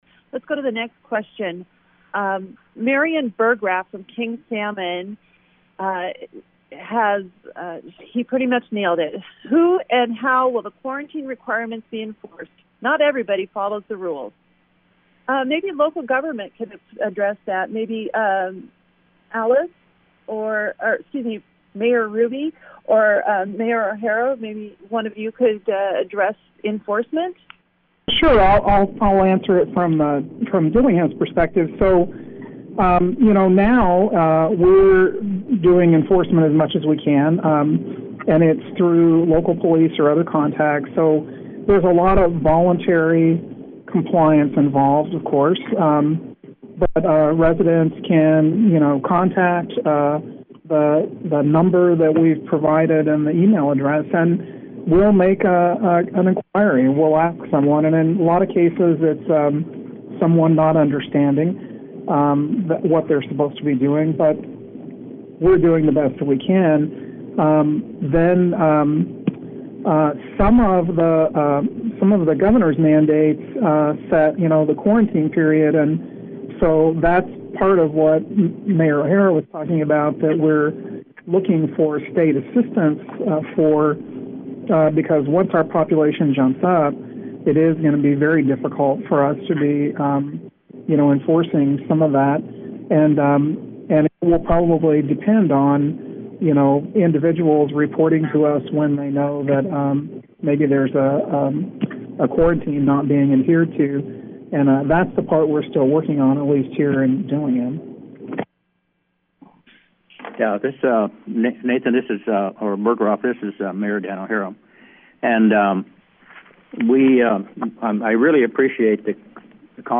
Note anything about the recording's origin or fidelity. Native communities request a cancellation of the 2020 salmon fishing season. Listen here to a Bristol Bay Town Hall discussion hosted by KDLG